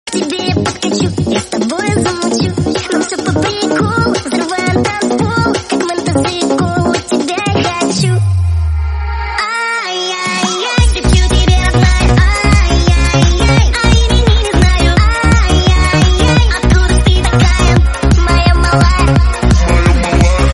веселые
быстрые
детский голос